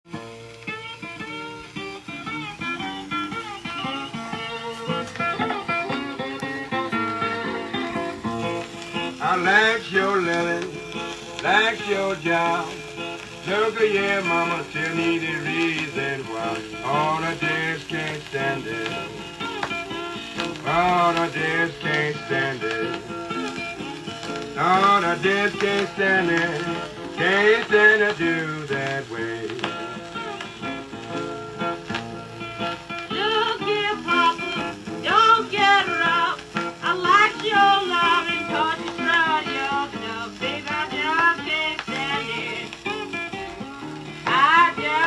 Scratches, NRs & Rim Chip NAP No skips!